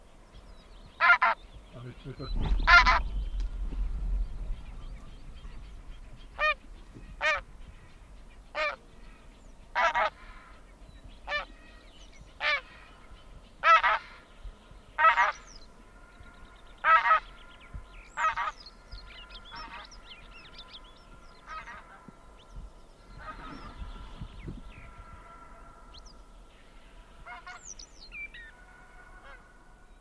Oie des moissons - Mes zoazos
oie-des-moissons.mp3